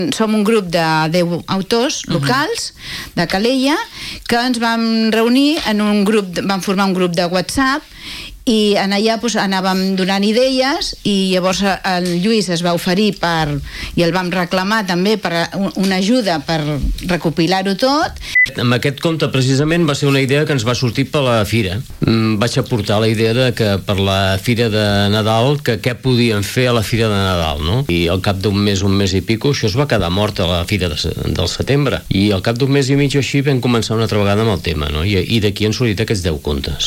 Aquest dilluns, una representació del grup ha passat per l’entrevista de RCT.